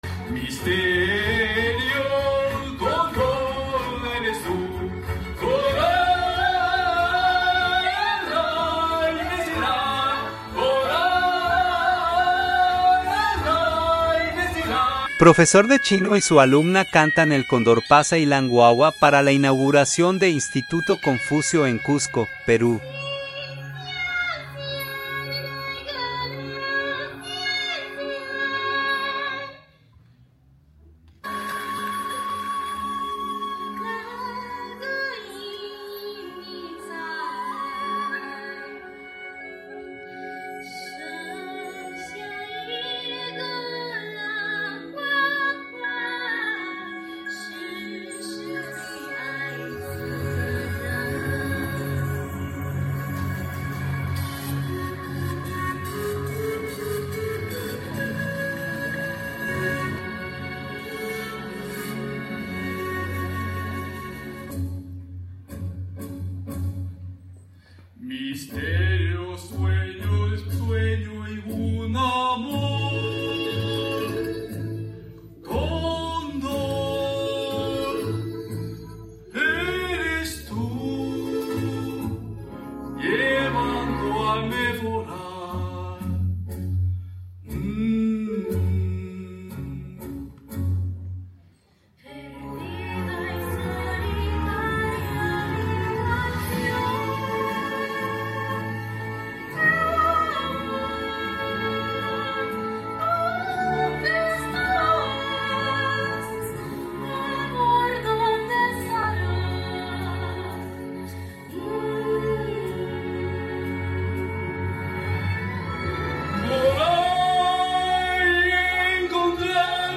Nos sentimos muy orgullosos de presentarla en la ceremonia de apertura del nuevo punto de enseñanza del Instituto Confucio en Cusco.